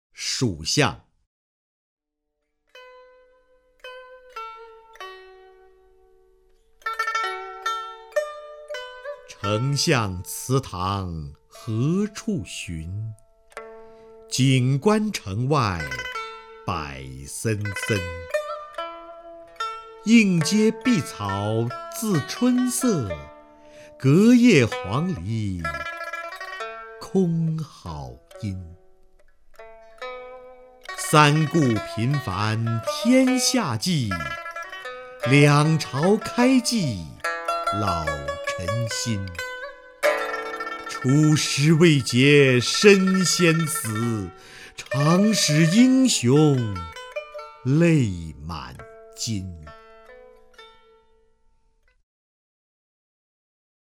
首页 视听 名家朗诵欣赏 瞿弦和
瞿弦和朗诵：《蜀相》(（唐）杜甫)